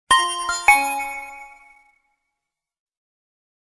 Message Tones